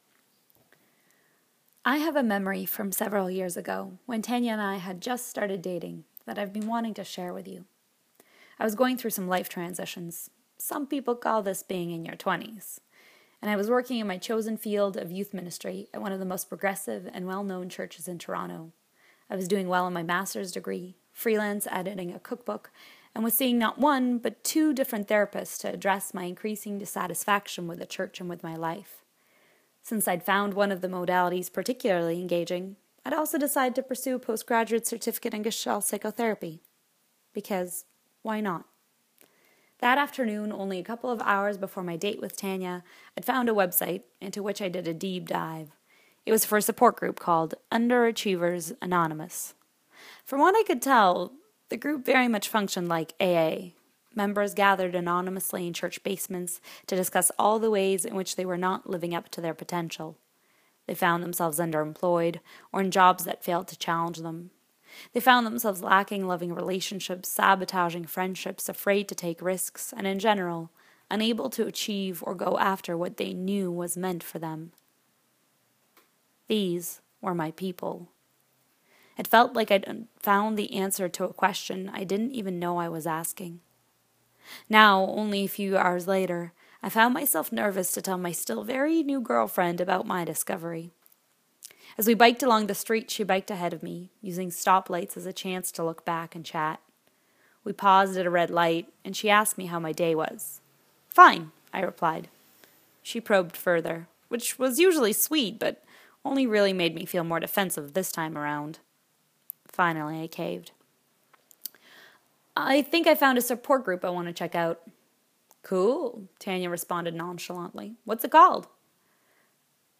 Love letters from me, read by me.